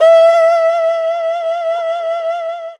52-bi10-erhu-f-e4.wav